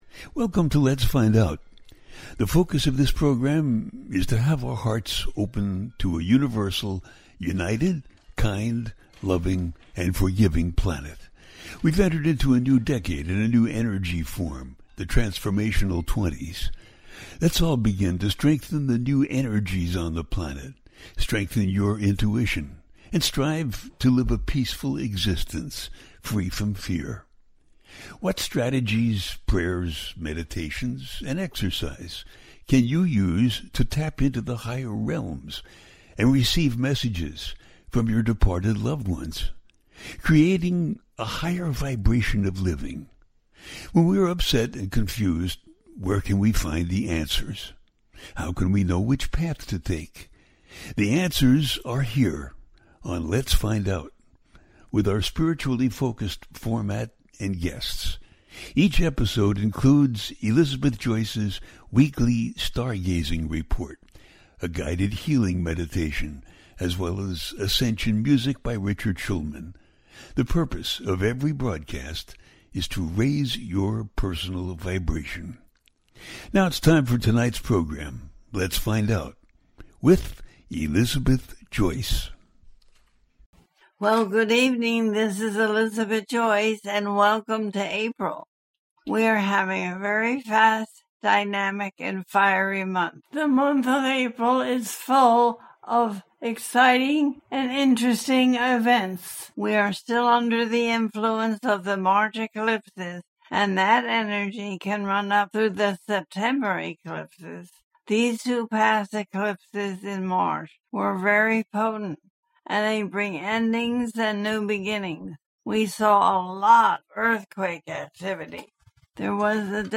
Full Moon In Libra And Early April 2025 - A teaching show